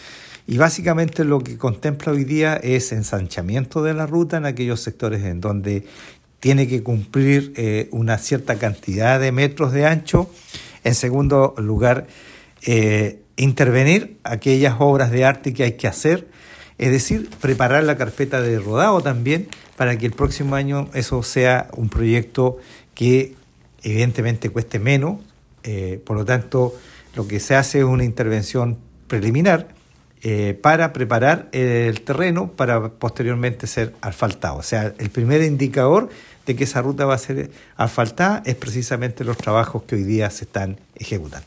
Los trabajos de ensanche y perfiladura no solo facilitarán un tránsito más seguro, sino que también permitirán el tránsito de vehículos de mayor capacidad, lo que es fundamental para las actividades comerciales y agrícolas de la zona. Además, se espera que la futura asfaltado de la ruta reduzca el tiempo de viaje y mejore la calidad de vida de los residentes, según señaló Bernardo Candia.